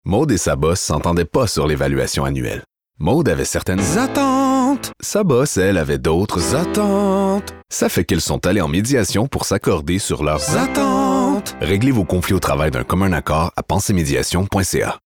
Voix annonceur – IMAQ
claire, confiante, chantée